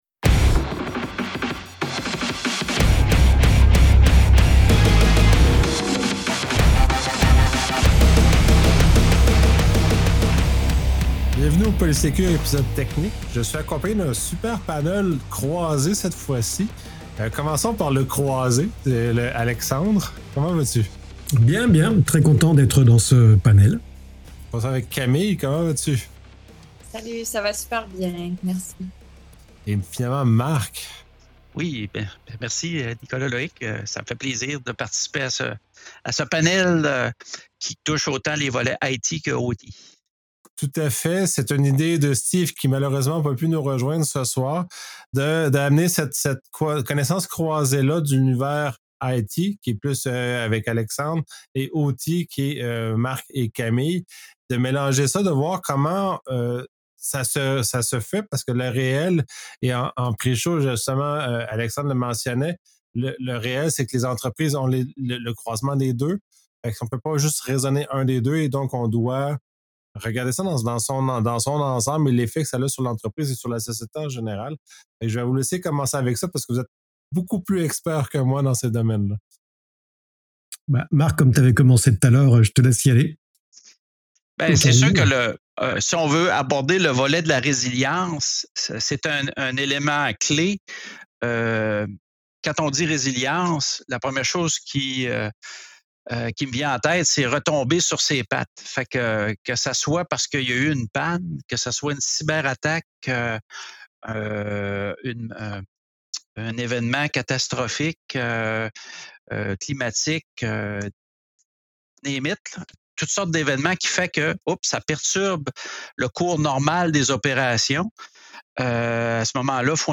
Ce podcast technique explore la résilience des systèmes opérationnels (OT) et informatiques (IT), réunissant trois experts qui partagent leur expérience sur les défis de continuité des opérations dans les environnements industriels modernes.